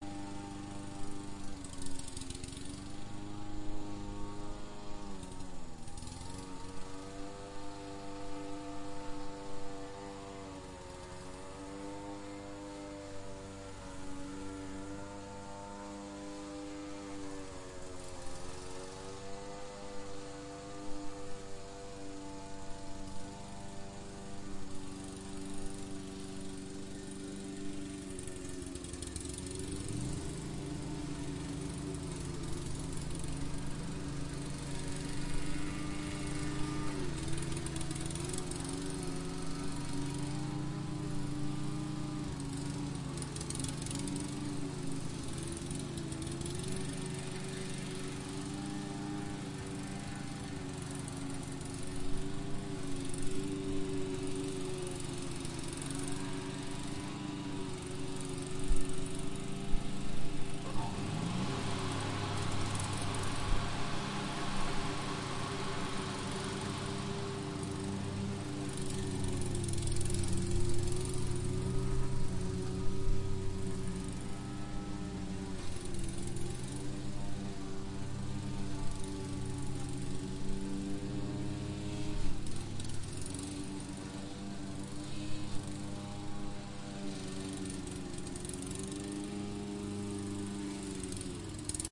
割草机 " 电动割草机圈
描述：电动割草机的声音响起